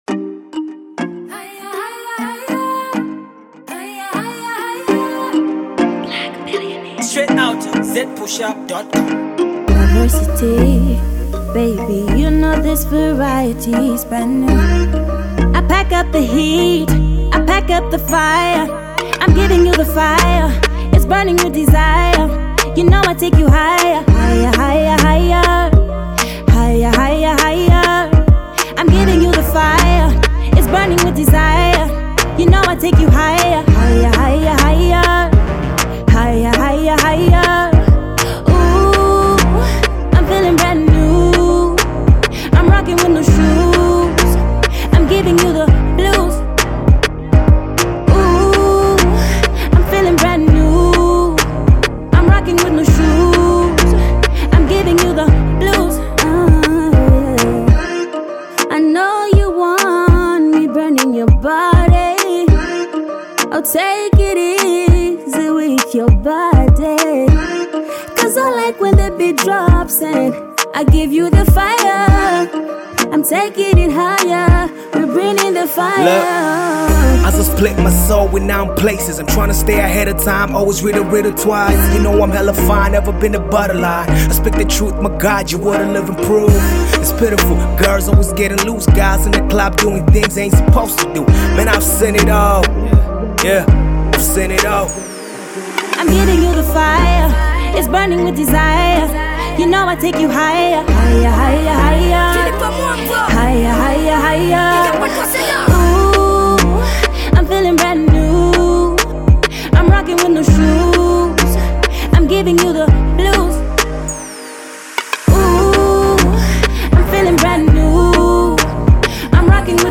finally has a bulk of artists on a single beat